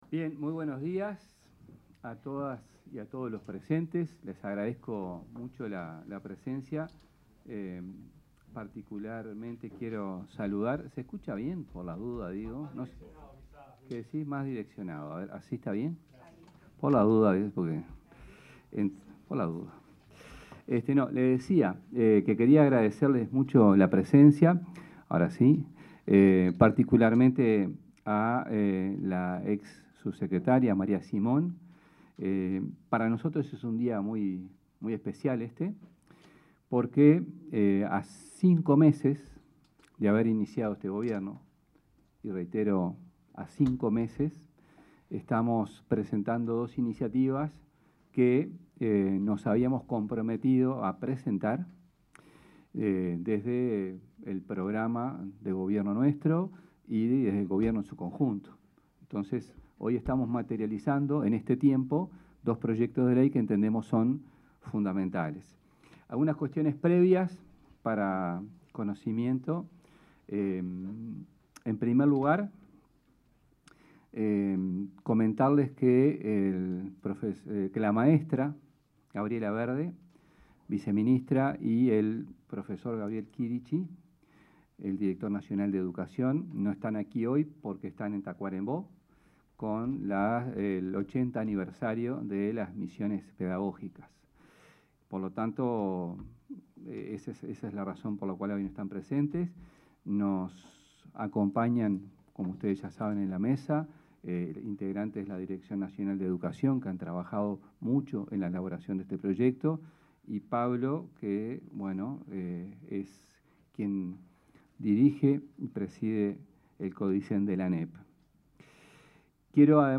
Palabras del ministro de Educación y Cultura, José Carlos Mahía